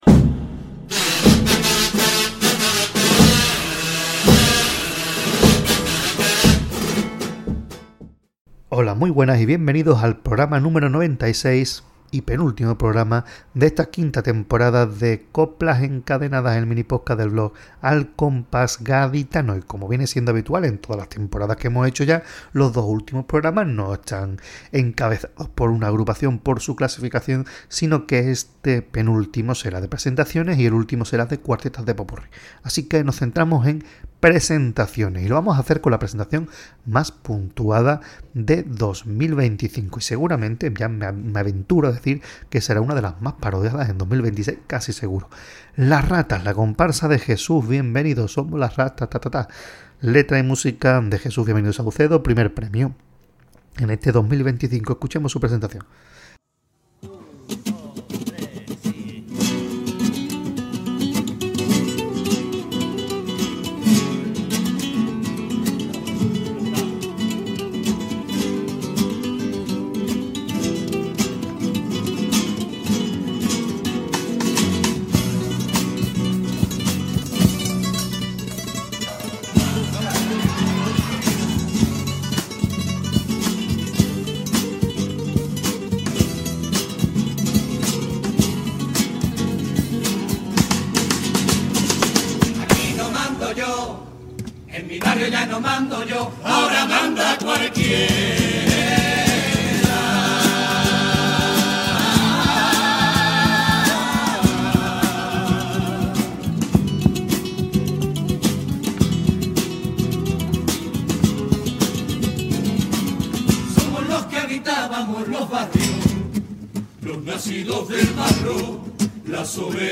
Por última vez en el mes de abril de 2024 traemos nuestra ración de ‘Coplas encadenadas’ y lo hacemos concluyendo con el repaso a los vencedores del pasado COAC, en este caso, en la modalidad del cuarteto.
Cuplés de preliminar
Pasodoble